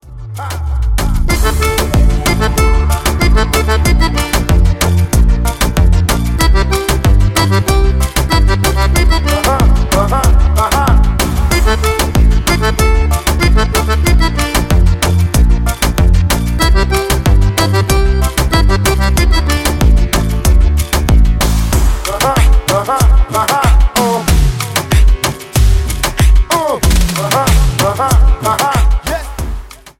REGGAETON  (03.55)